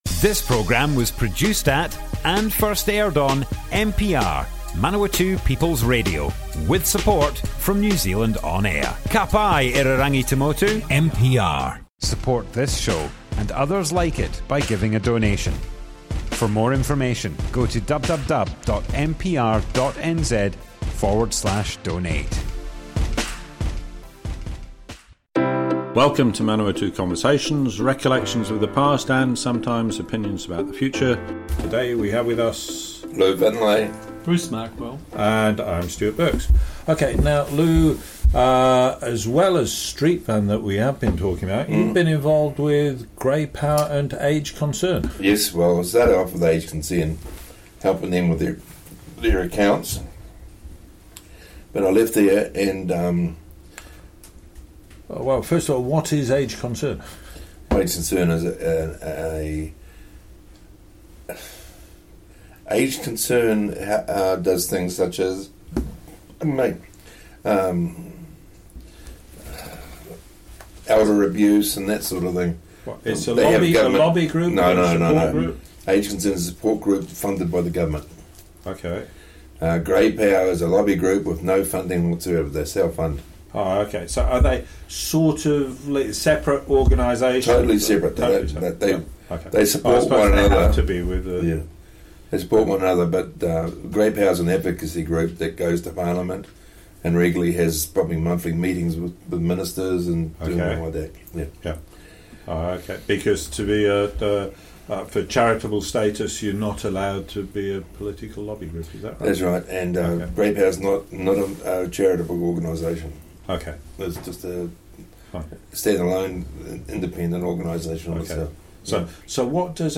Street Van - Manawatu Conversations More Info → Description Broadcast on Manawatu People's Radio, 2nd November 2021.
oral history